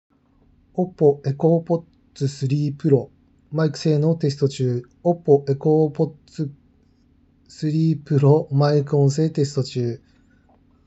こもりも少なくクリアな音質
OPPO Enco Buds3 Proのマイク性能テスト